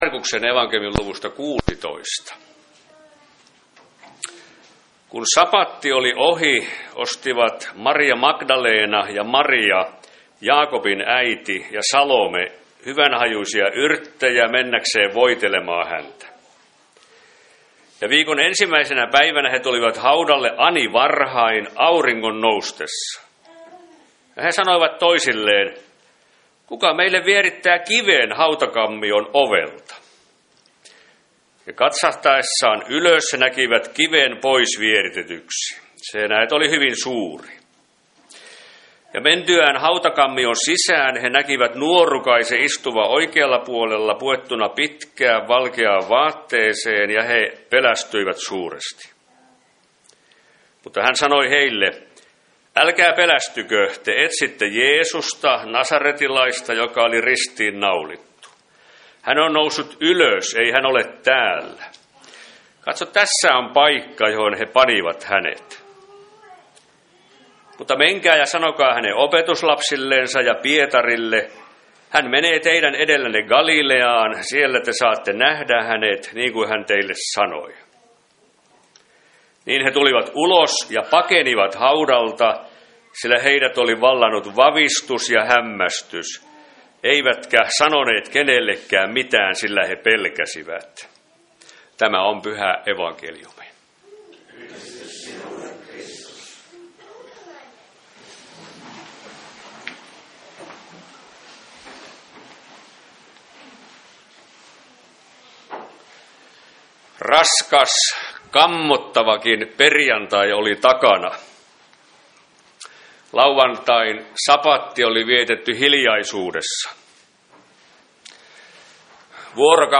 Pääsiäismessu Hyvän Paimenen Kappelilla 5.4.2026